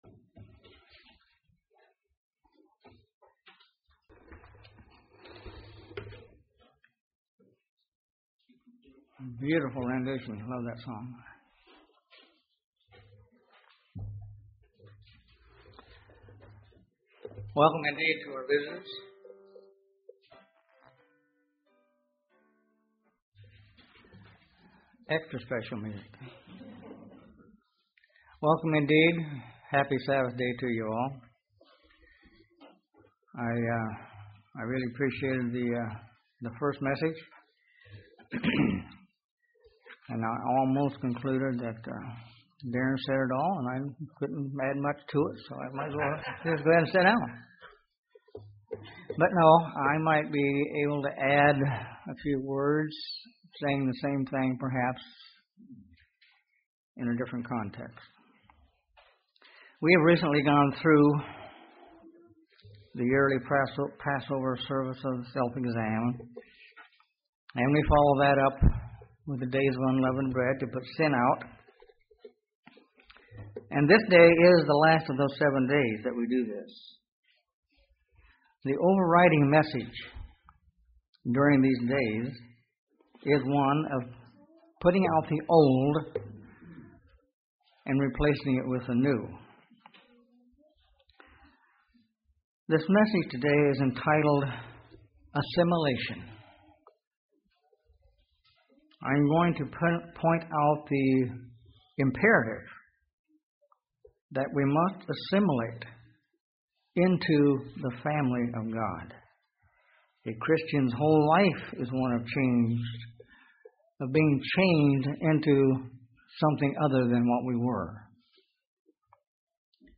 Sermons
Given in Huntsville, AL Gadsden, AL